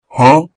Huh